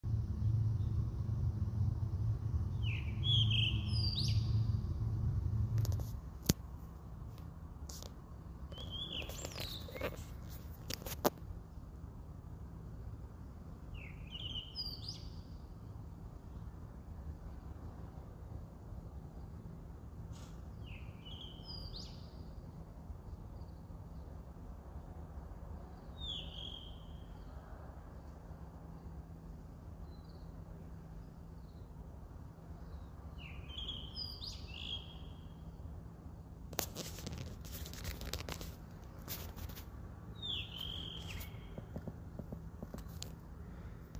中庭に早朝響くイソヒヨドリの歌声
いそひよとり.mp3